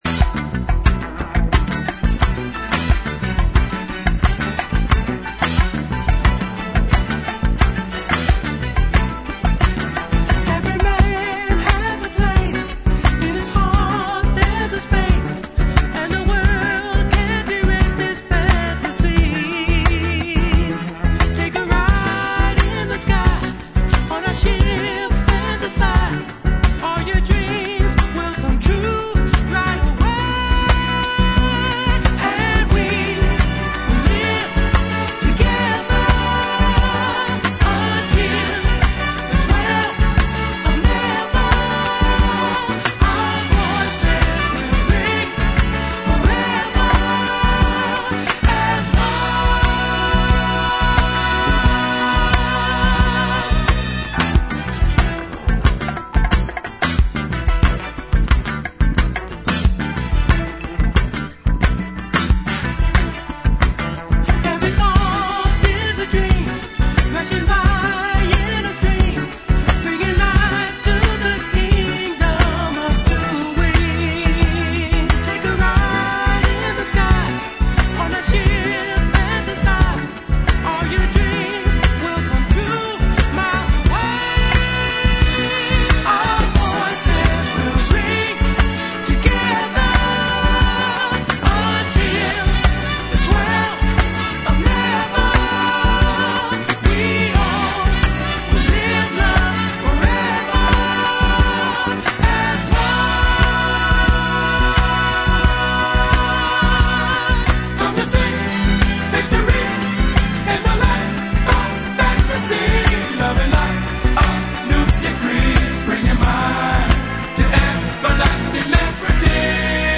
ジャンル(スタイル) DISCO / SOUL